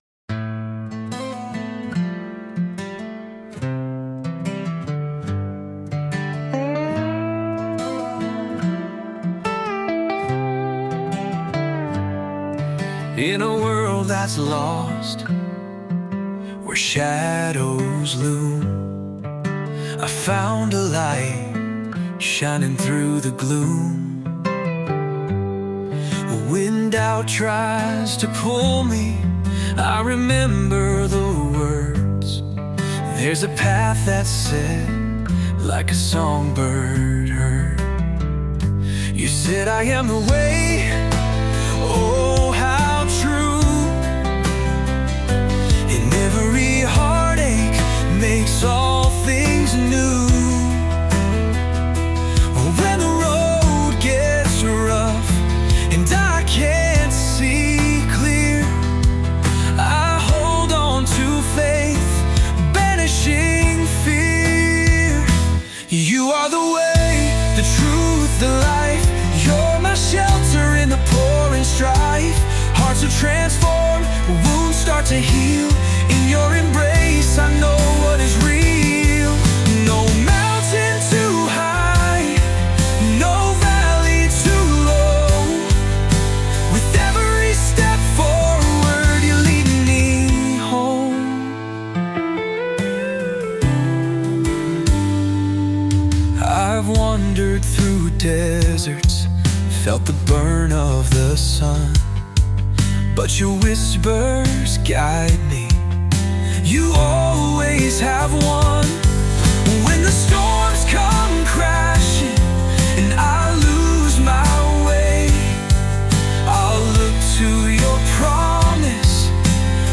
Christian Music